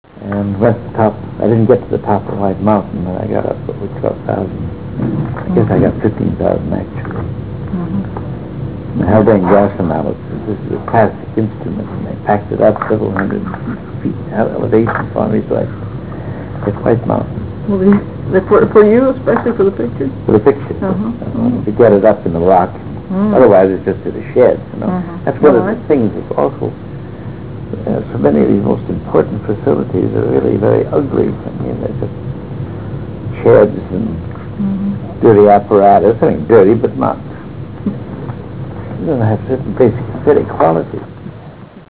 336Kb Ulaw Soundfile Hear Ansel Adams discuss this photo: [336Kb Ulaw Soundfile]